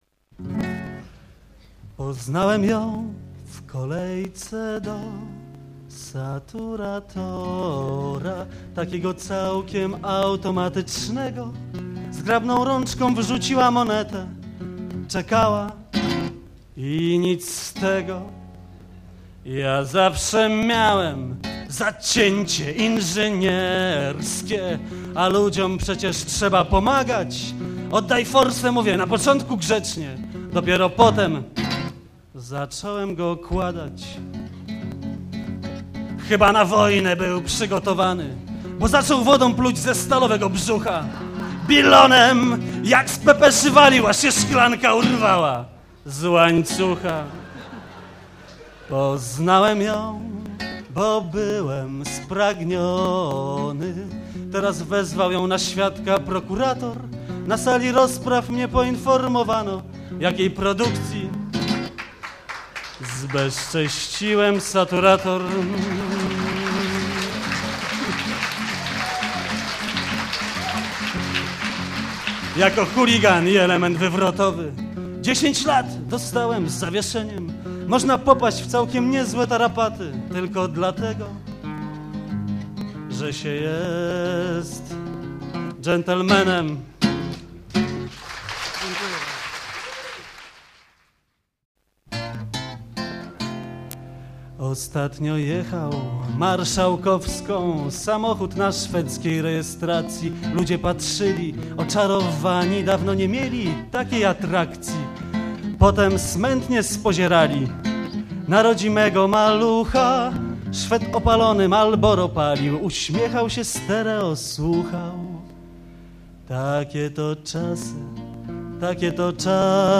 OPPA '85: Koncert Byłych Laureatów (W-wa, klub HYBRYDY) [dokument dźwiękowy] - Pomorska Biblioteka Cyfrowa